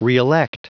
Prononciation du mot reelect en anglais (fichier audio)
Prononciation du mot : reelect